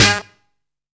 SAXY 3 STAB.wav